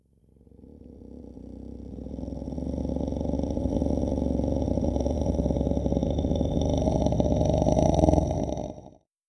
24. Sinister Slumber Growl.wav